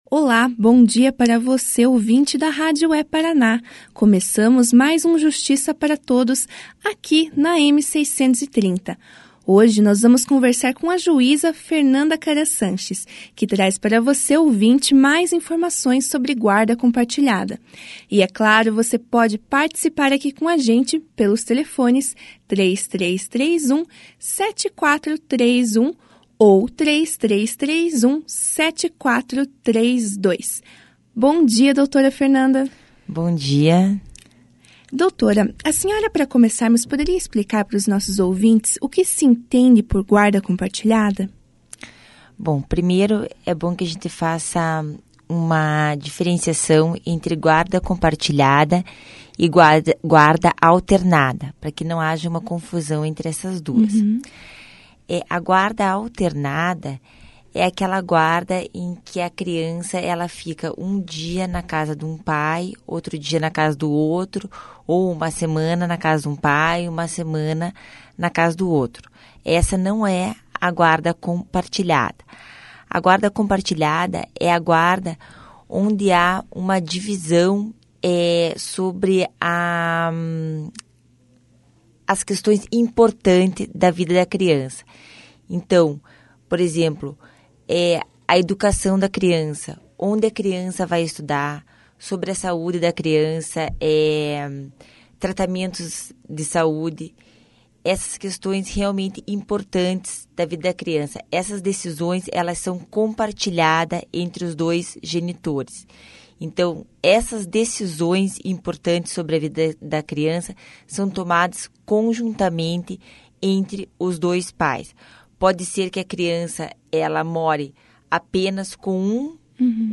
A Associação dos Magistrados do Paraná trouxe hoje (6) ao estúdio da rádio É-Paraná a juíza Fernanda Karam de Chueiri Sanches. A magistrada explicou, na ocasião, como funciona a guarda compartilhada.